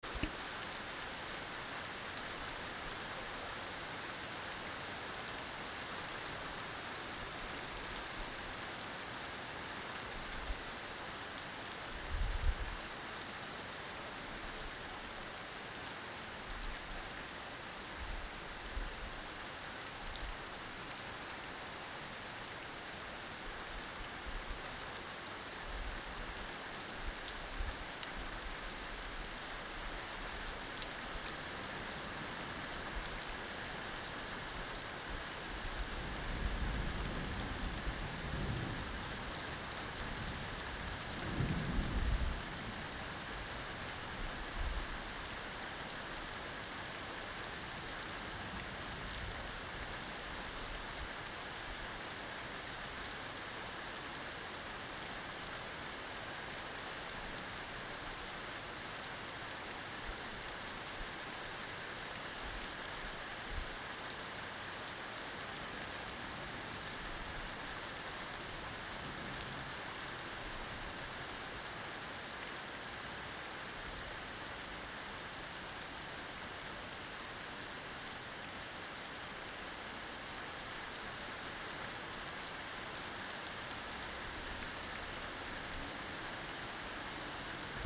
{{声音|声音-下雨背景音.mp3|循环自动播放}}